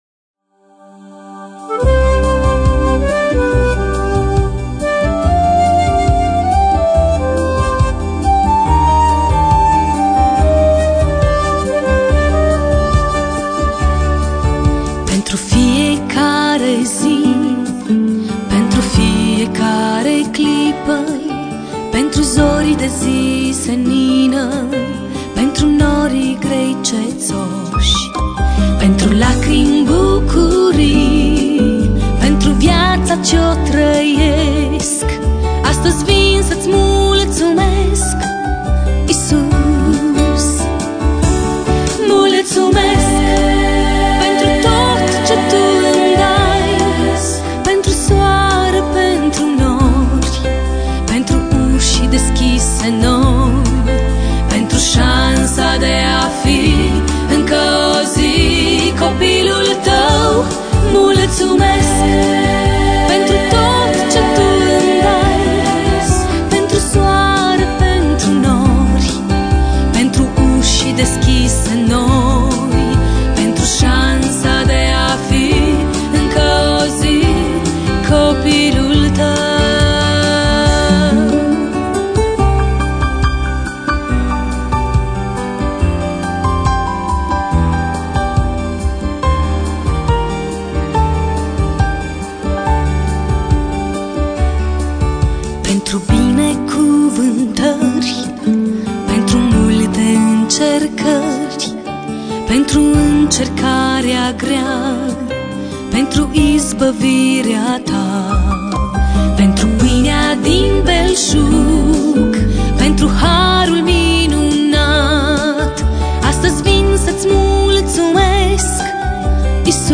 Next audio materials were recorded during our church services.